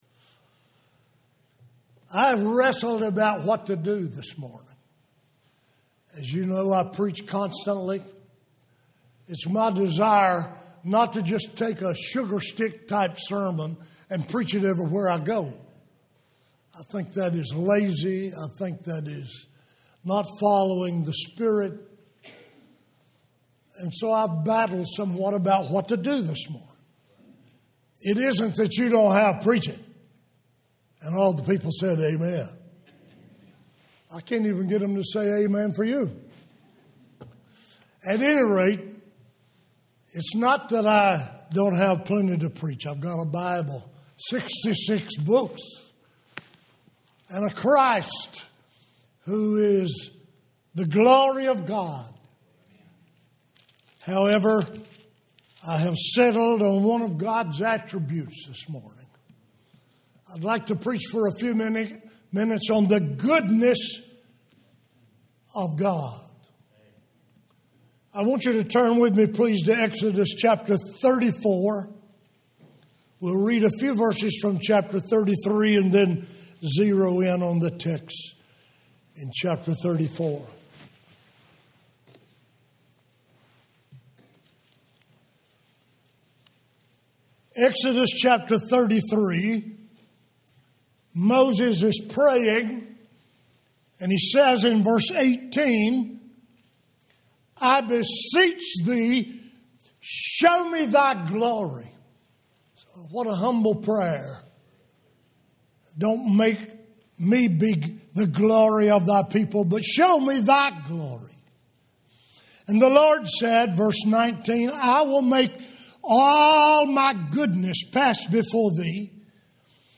Home › Sermons › The Goodness Of God